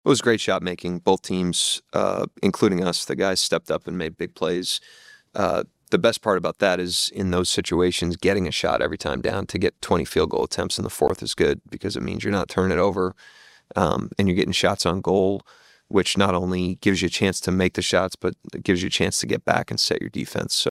Head coach Mark Daigneault talked about the team's ability to make shots.